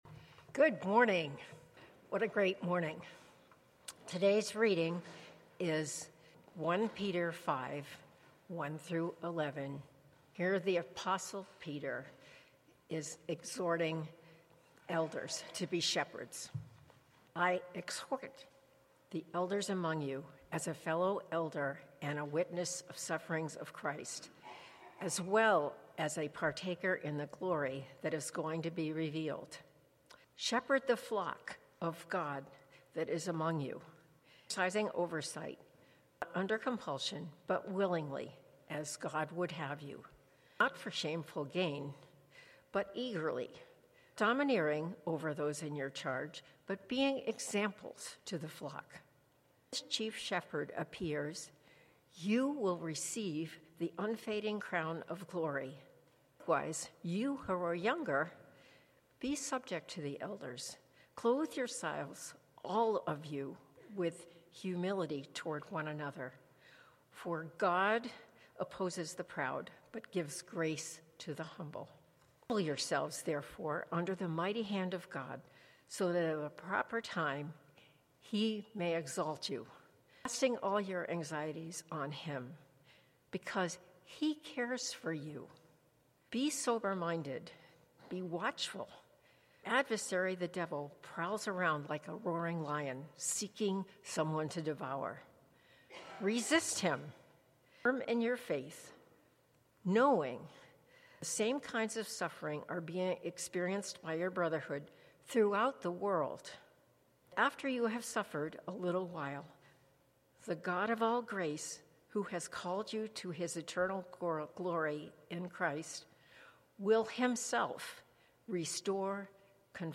Passage: 1 Peter 5:5-6 Sermon